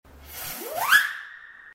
Funny Firework Sound Sound Button: Unblocked Meme Soundboard